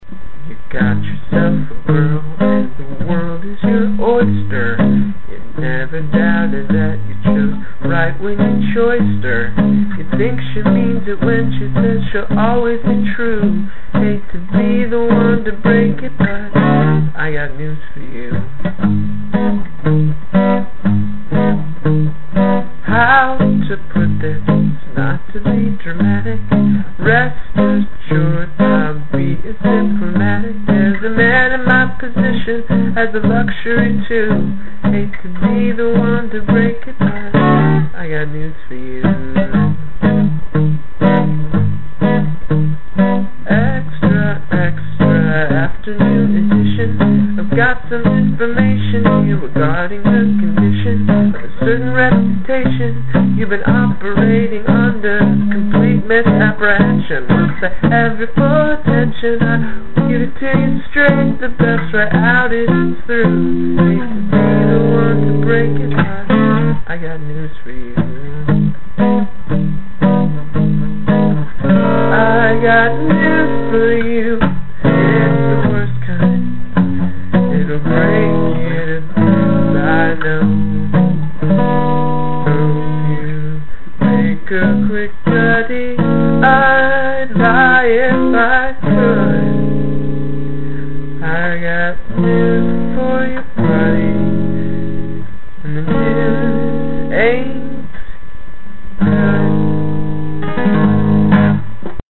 i wrote the lyrics for the first and third stanzas months ago, forgot about them completely, and then rediscovered them last night when i added words for the second stanza and "chorus". i wrote the music this morning. the tempo should be pretty even. on this, i'm drawing out the changes in the chorus, but that's just because i'm trying to remember how to play them and i wanted to get it down fast.
also, this should probably be sung with a new york accent. i tried, but i don't have one.